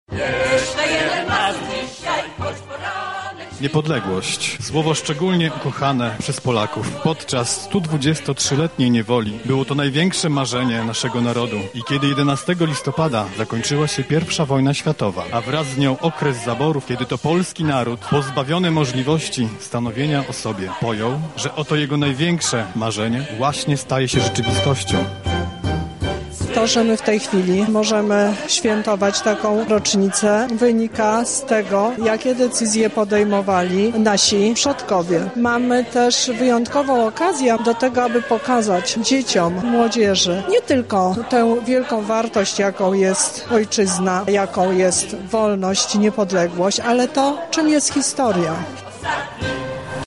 Wystąpiły podczas niego zespoły cywilne i wojskowe. Prezentowane były utwory muzyki ludowej oraz wojskowej z czasów Józefa Piłsudskiego. Na miejscu był nasz reporter.